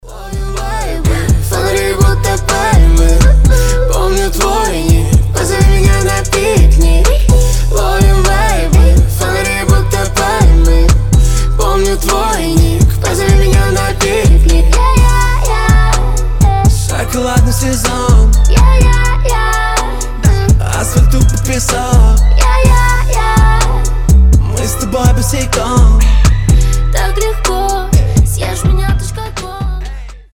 • Качество: 320, Stereo
позитивные
веселые
заводные
дуэт